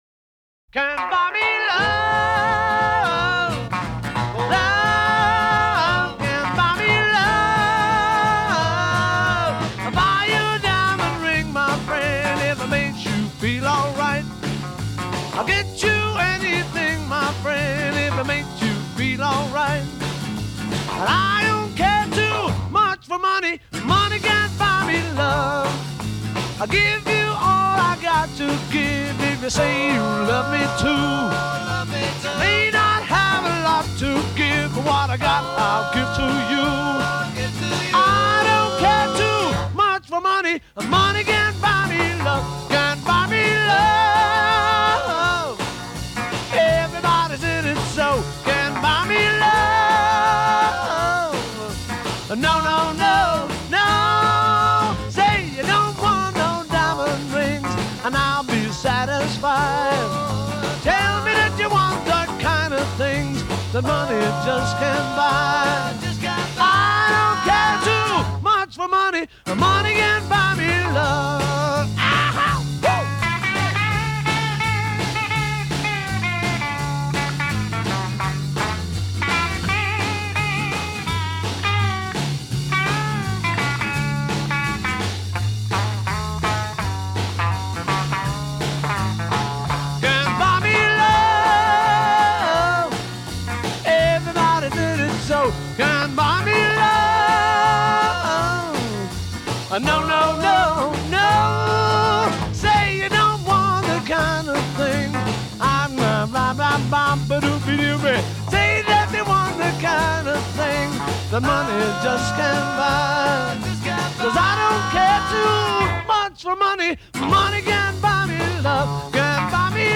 Recorded In Paris